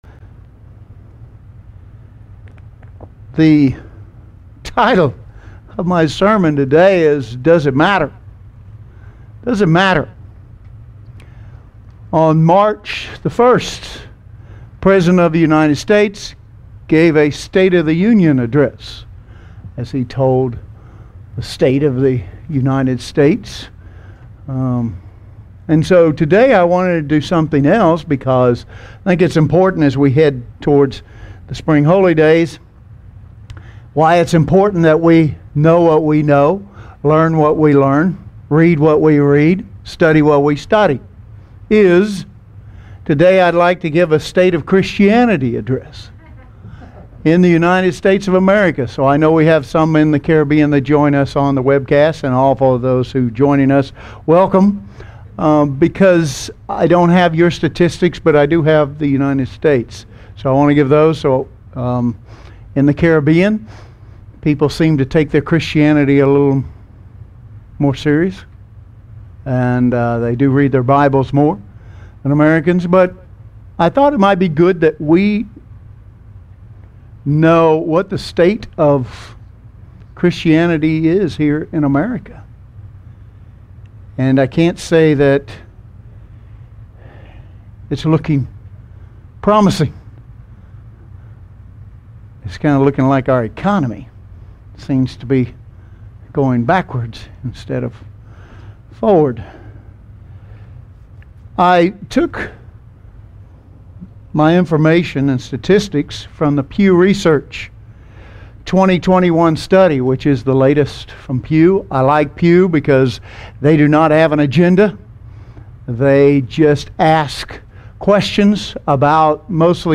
| United Church of God